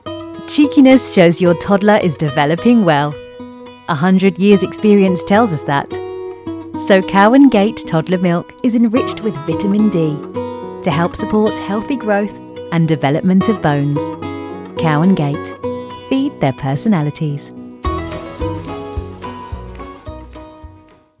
Mother Mum Friendly Family
RP ('Received Pronunciation')